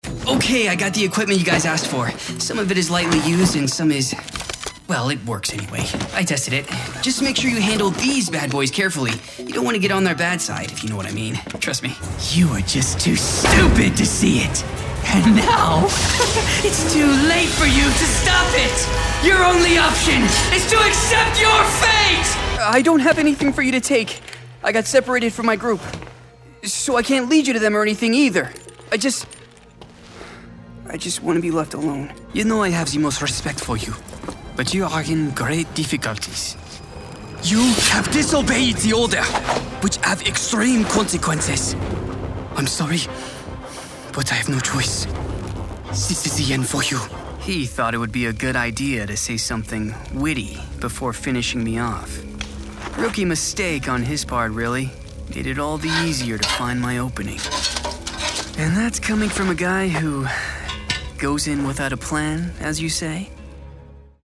Friendly Conversational Commercial Demo